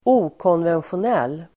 Ladda ner uttalet
okonventionell.mp3